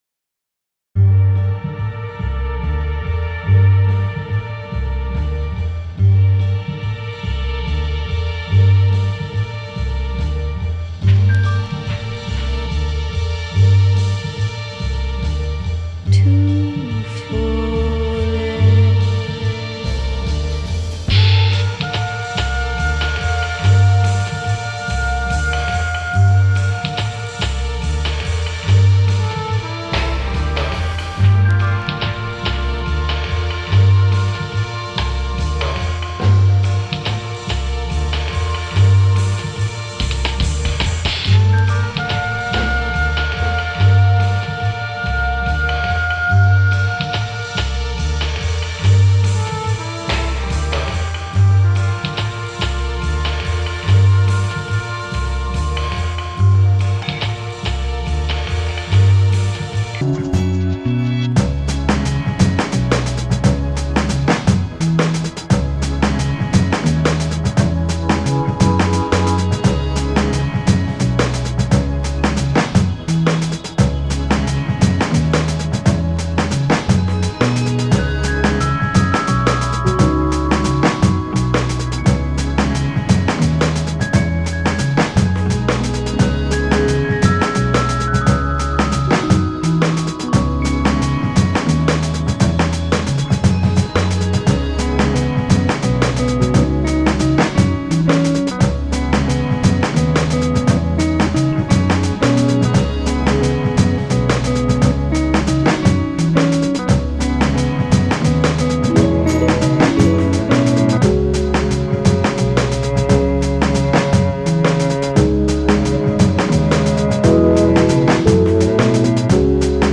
Electronica & leftfield
Genre: Electronica/Ambient/Leftfield